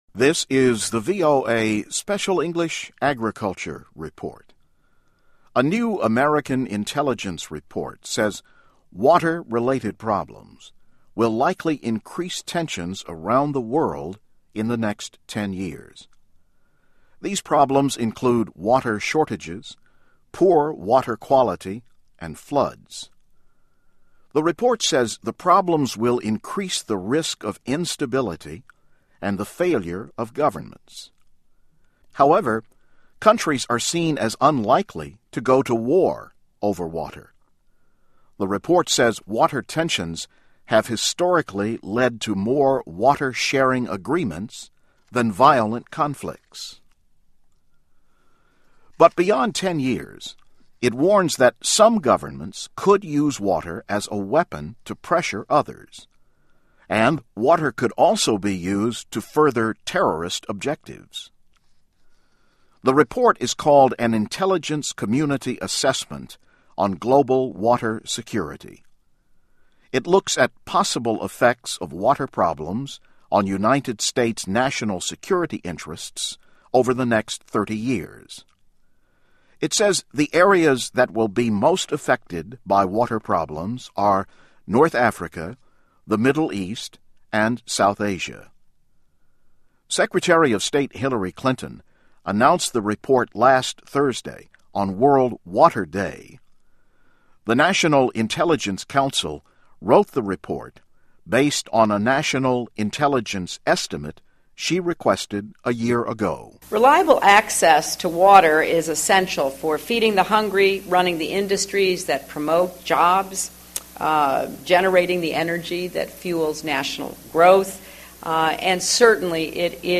VOA Special English, Agriculture Report, Water Problems and the World